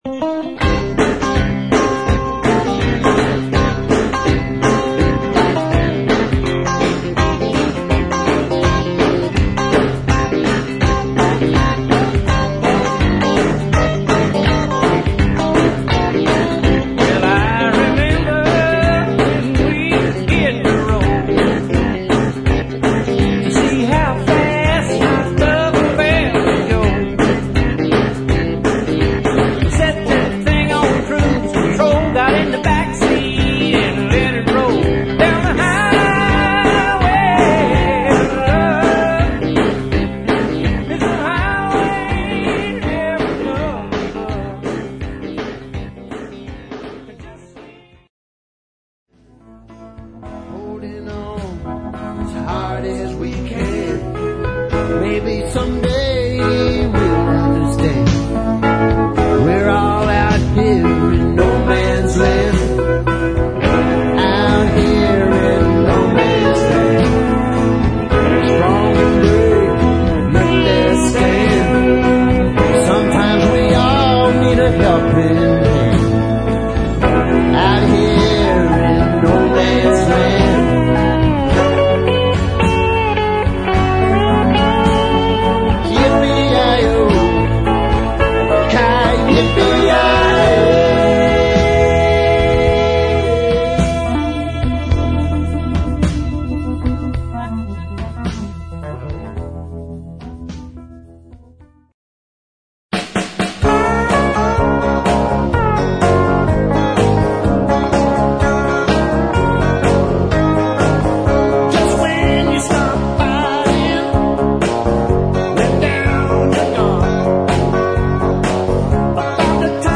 vocals, guitars, keyboards, percussion
guitars, flute,
pedal steel & vocals
drums & vocals
guitar, bass guitar
bass guitar
vocals, guitar